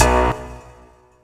GUnit Keyz2.wav